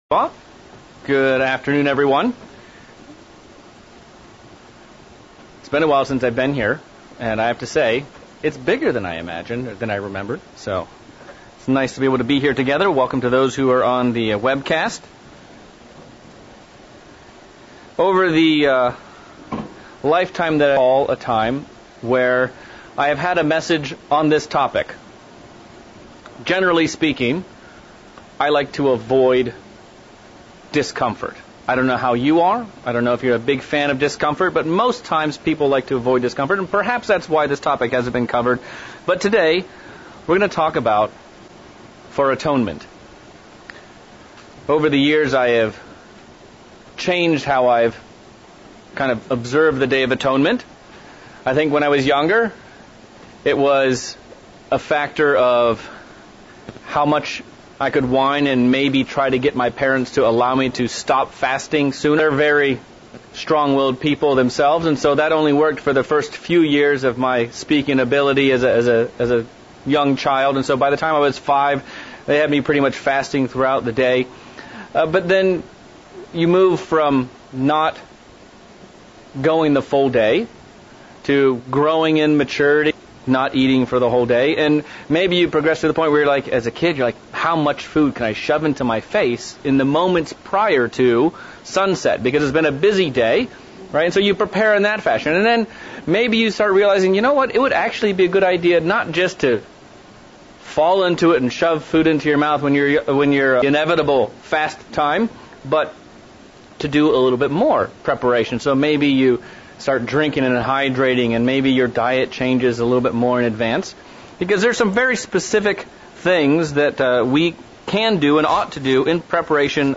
Sermon looking at the Day of Atonement, it's purpose, it's symbolism, and how we can prepare for God's special Holy Day